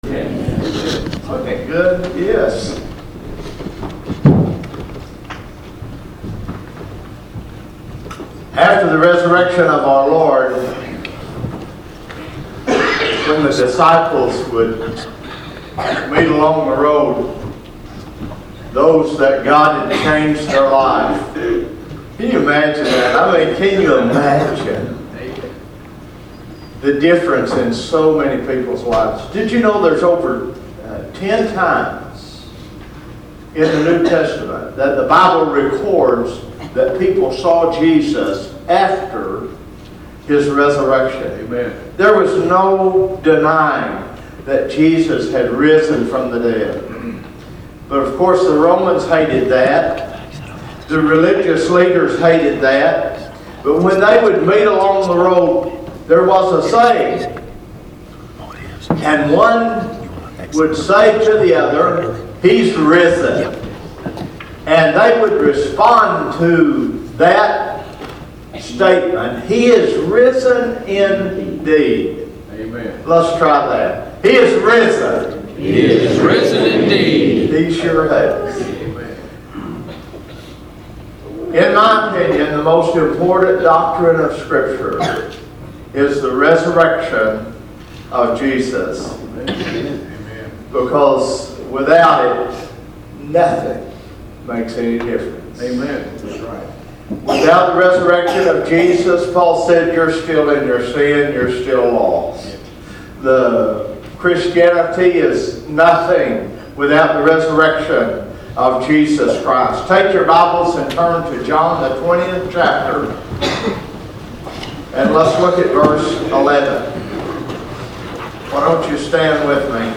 Required fields are marked * Comment * Name * Email * Website ← Newer Sermon Older Sermon →